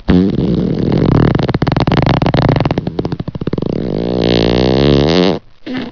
Klik på billedet for at høre en tiger prut.
Den havde spist en gaur kalv og lå og fordøjede og pruttede. Jeg fik lavet en lydoptagelse og jeg fik taget nogle billeder som du kan se.
Tiger-prut.wav